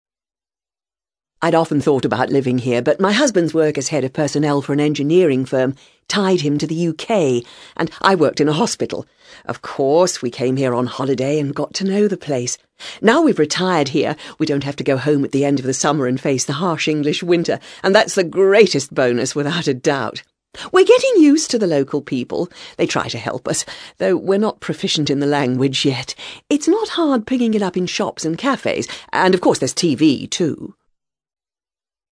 ACTIVITY 112: You will hear five short extracts in which British people are talking about living abroad.